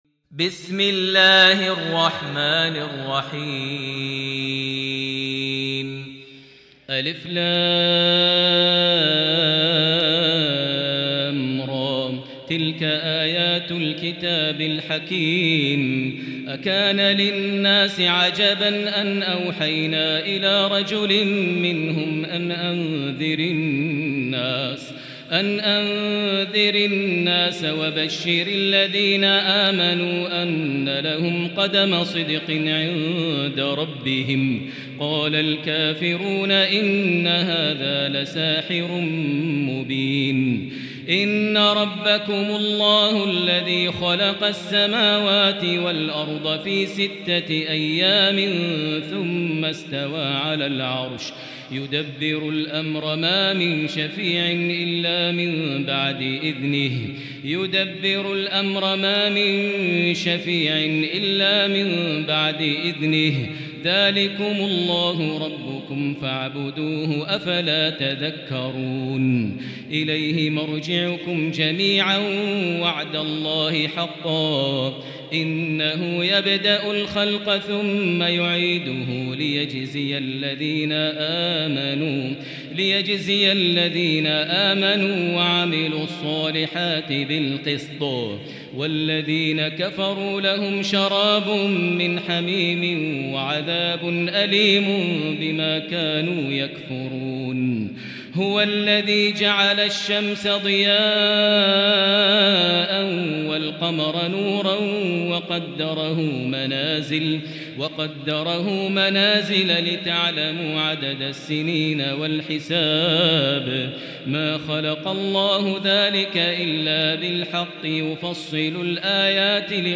المكان: المسجد الحرام الشيخ: معالي الشيخ أ.د. بندر بليلة معالي الشيخ أ.د. بندر بليلة فضيلة الشيخ ماهر المعيقلي يونس